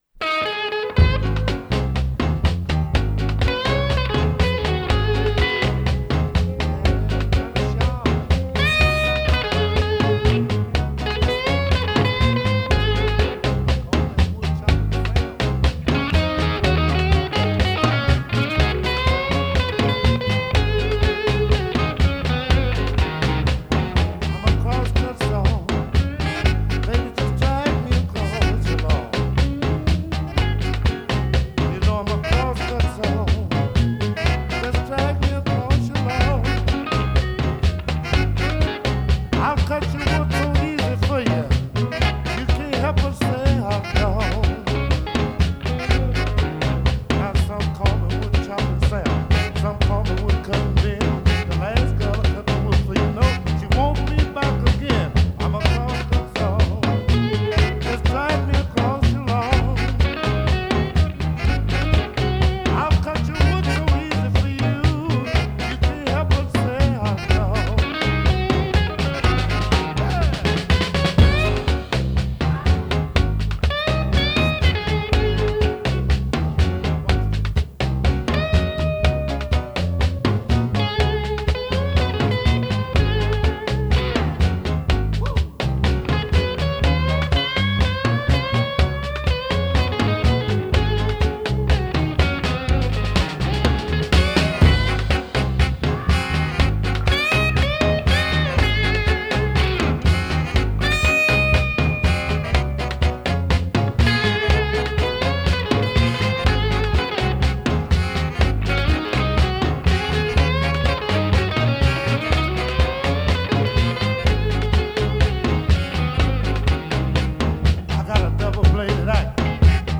Blues
Guitare